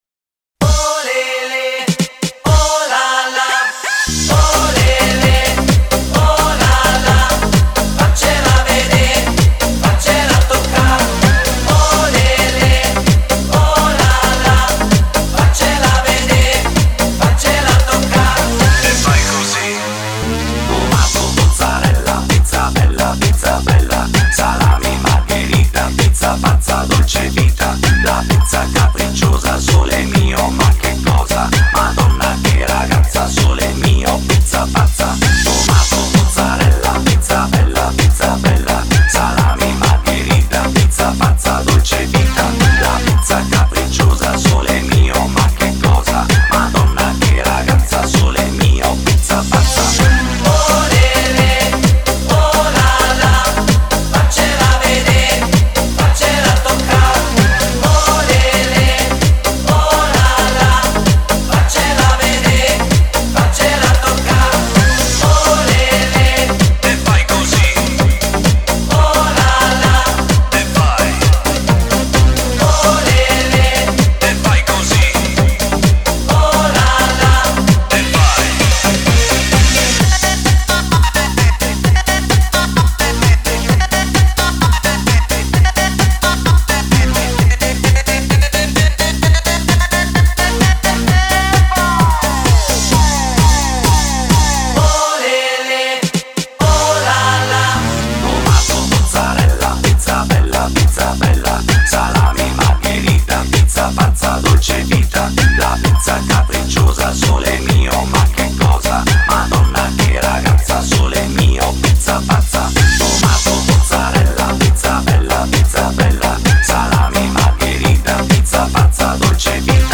خواننده زن